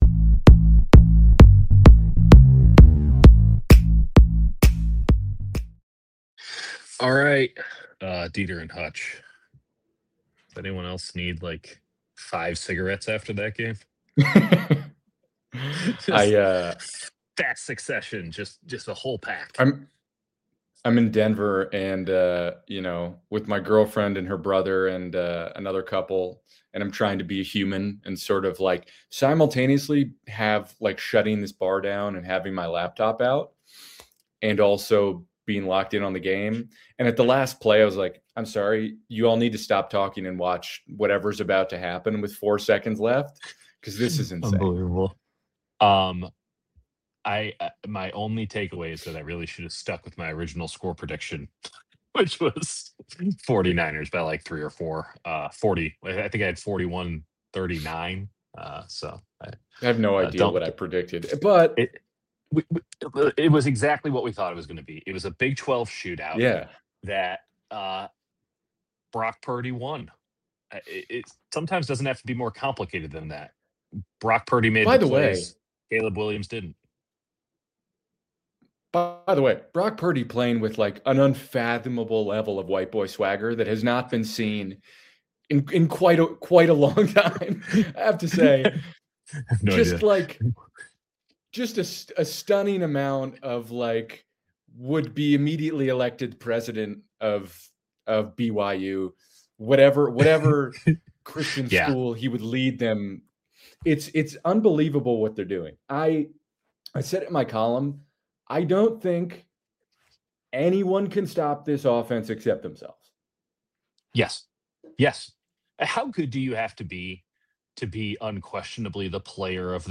We Need Inhalers | 49ers-Bears Postgame LIVE